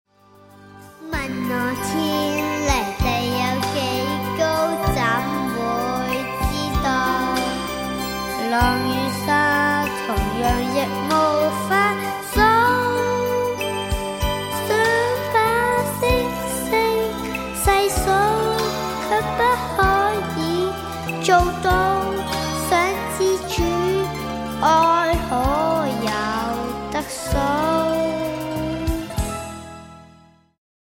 充滿動感和時代感